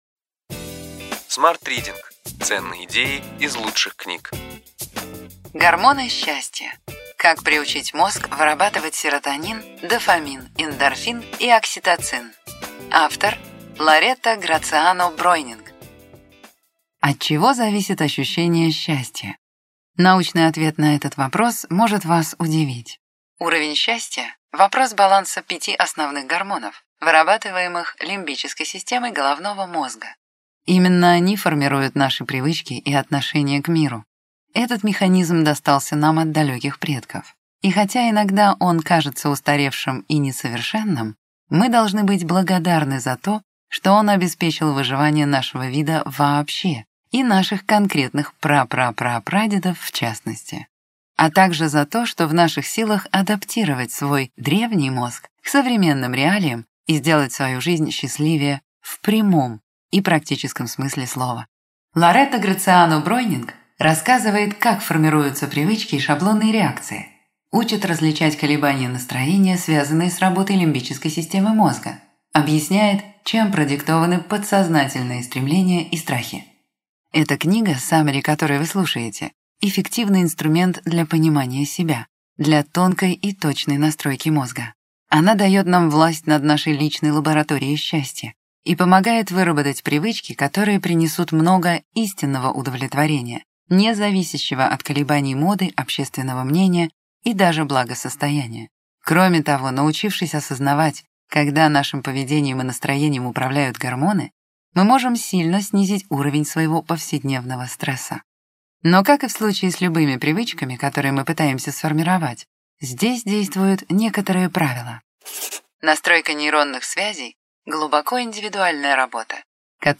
Аудиокнига Ключевые идеи книги: Гормоны счастья. Как приучить мозг вырабатывать серотонин, дофамин, эндорфин и окситоцин.